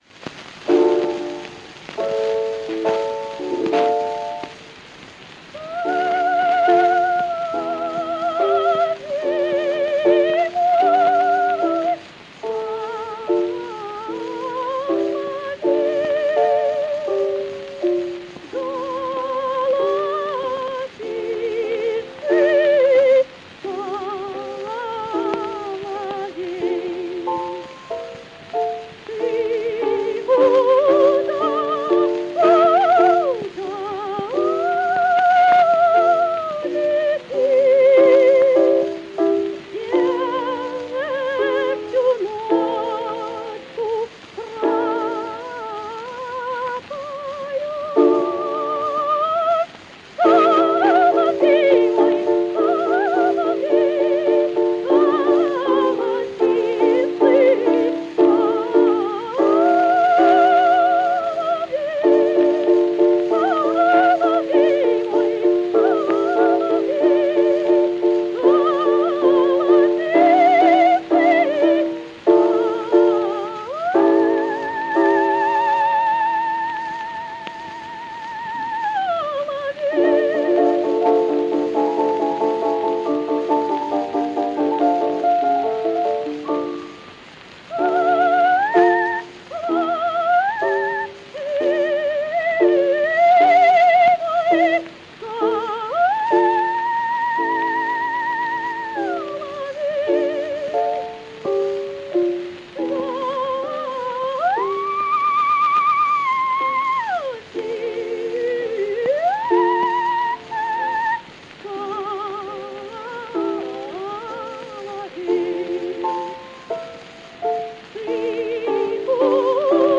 Романс «Соловей» звучит в исполнении Антонины Неждановой (грамзапись 1908 года; скачать):
Пластинка